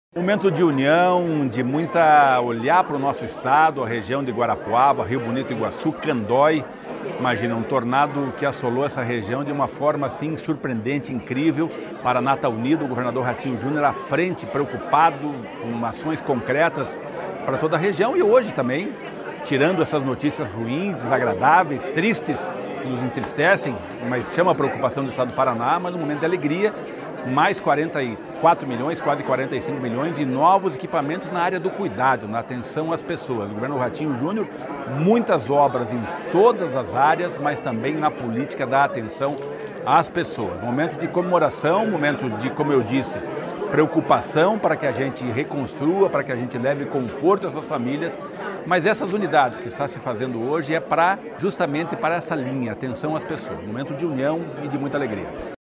Sonora do secretário do Desenvolvimento Social e Família, Rogério Carboni, sobre a liberação de recursos para construção de 37 novos CRAS e CREAS